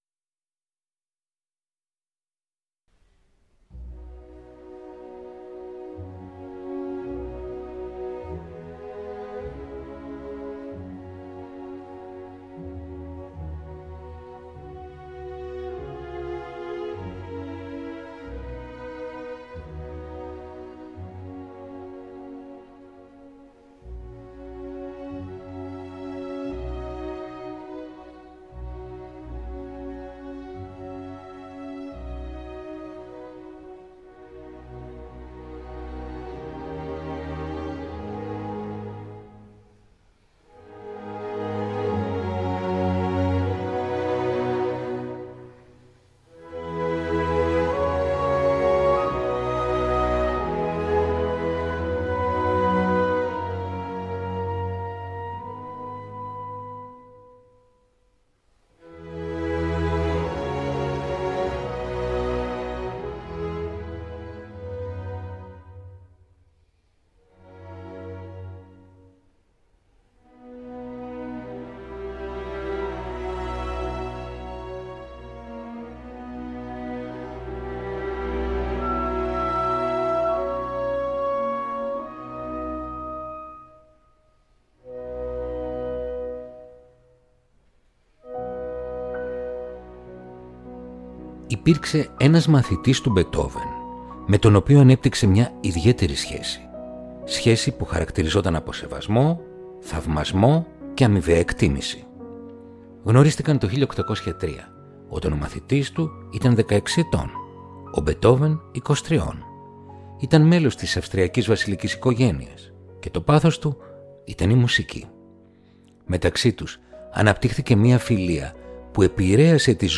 Ludwig van Beethoven Κοντσέρτο για Πιάνο Αρ. 5 σε Σολ Μείζονα